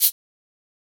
MZ Shaker [Plugg Shaker #1].wav